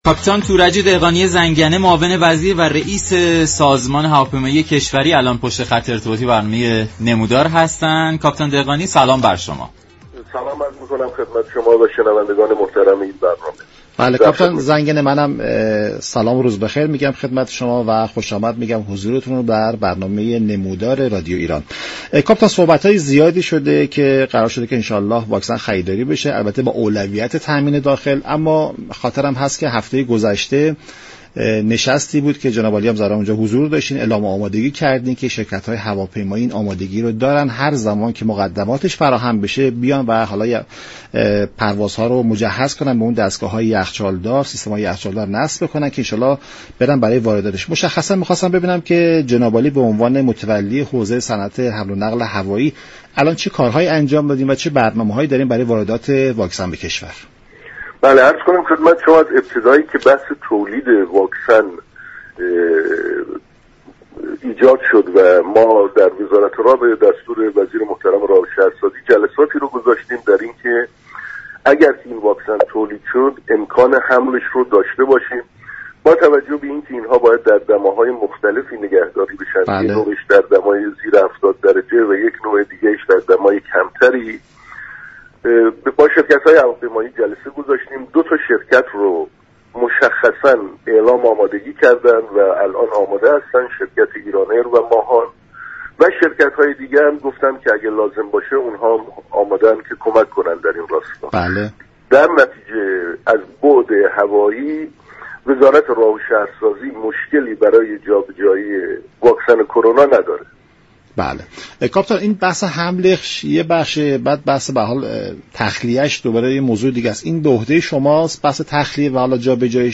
به گزارش شبكه رادیویی ایران، كاپیتان تورج دهقانی زنگنه معاون وزیر و رییس سازمان هواپیمایی كشوری در برنامه «نمودار» به برنامه ها و اقدامات سازمان هواپیمایی كشور برای واردات واكسن كرونا پرداخت و گفت: در بحث واردات واكسن كرونا هم اكنون دو شركت هواپیمایی ایران ایر و ماهان اعلام آمادگی كرده اند و وزارت راه و شهرسازی در حوزه هوایی مشكلی برای جابجایی واكسن ندارد.